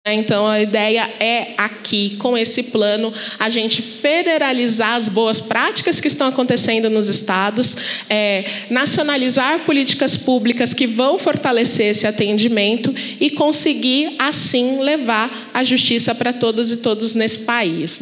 Fala secretária Sheila Carvalho no lançamento do Plano Nacional Defensoria em Todos os Cantos.mp3 — Ministério da Justiça e Segurança Pública